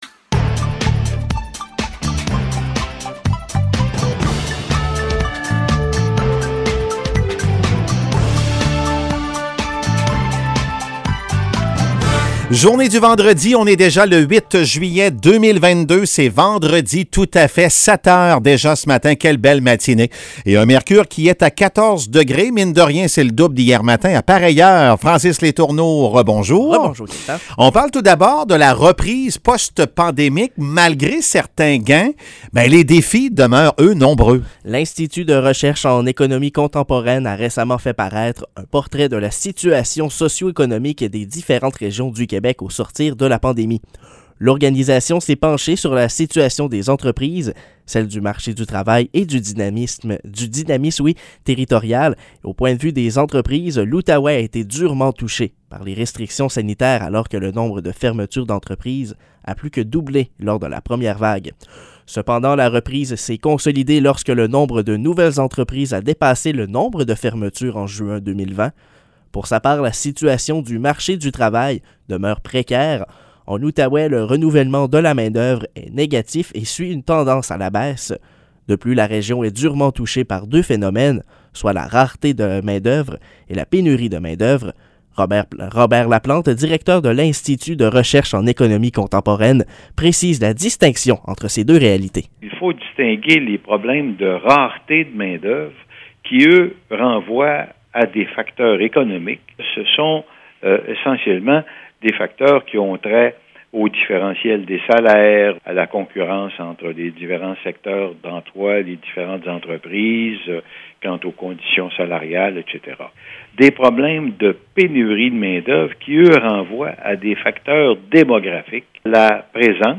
Nouvelles locales - 8 juillet 2022 - 7 h